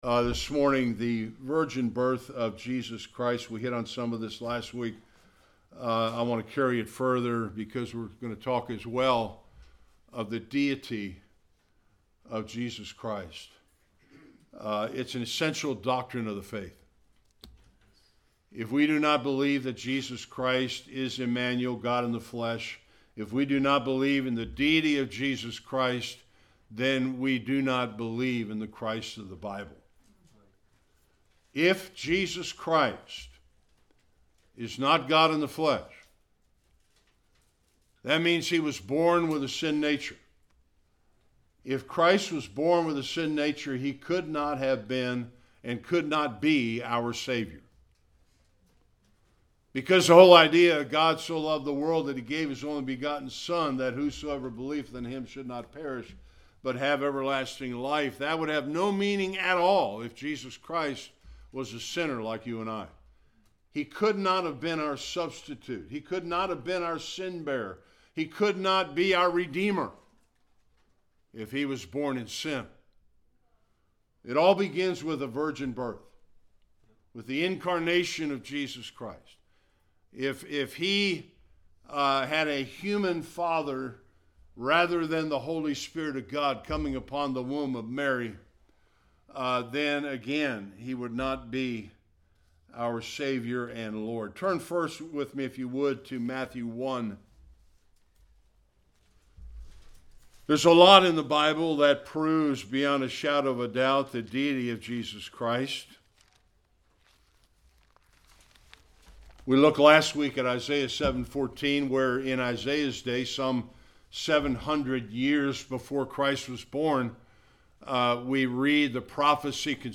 Various passages Service Type: Sunday Worship The virgin birth of Jesus Christ is an essential doctrine of the faith.